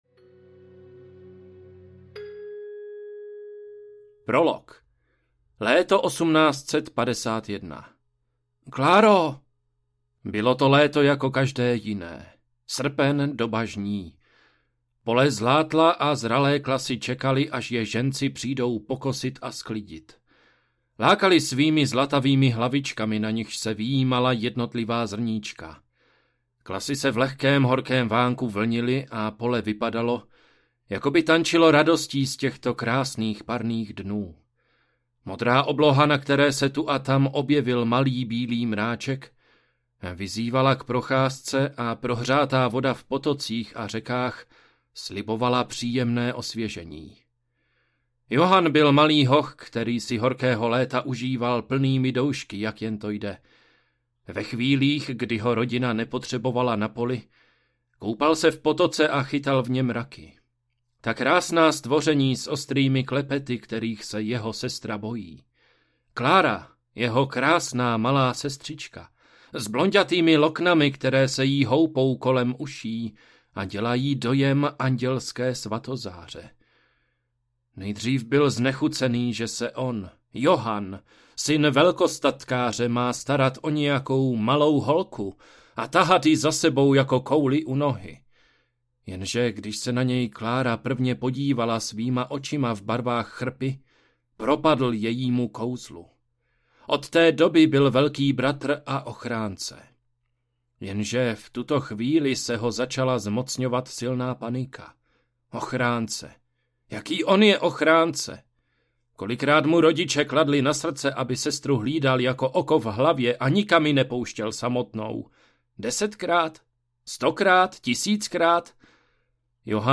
Prokletí Schwartzovy vily audiokniha
Ukázka z knihy